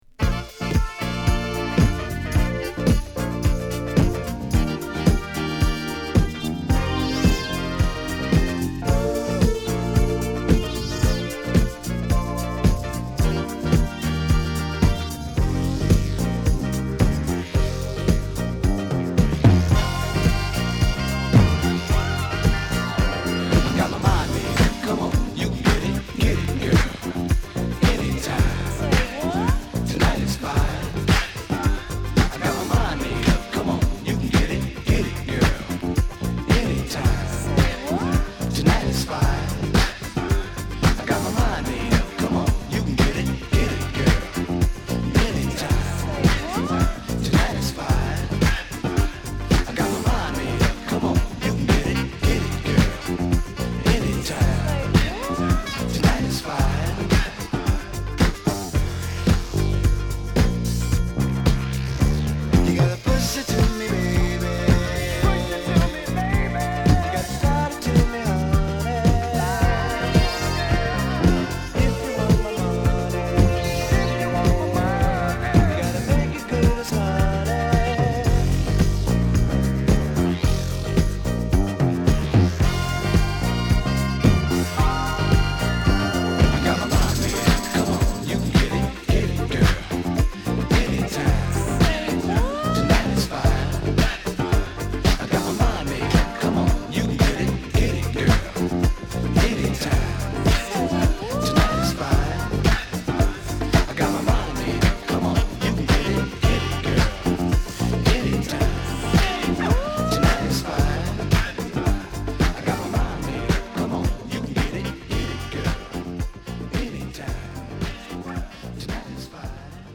フィラデルフィアのディスコバンド